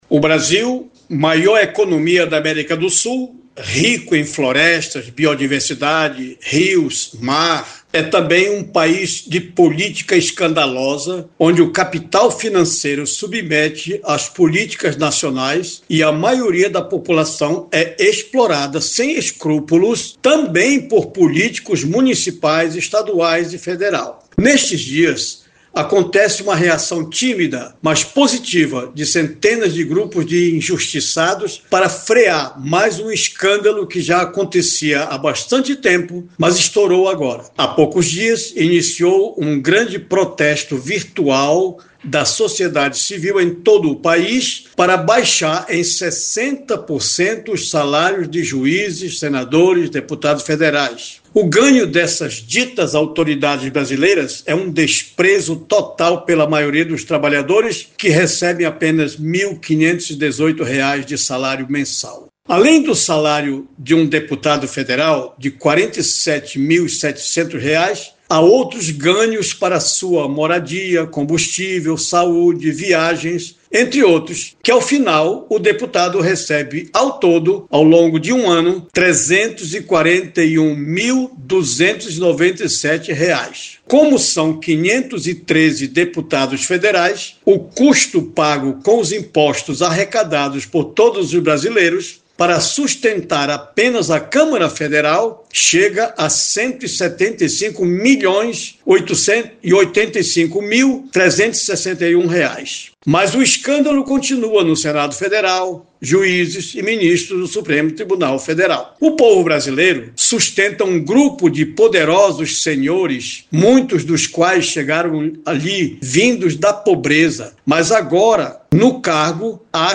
Acompanhe o editorial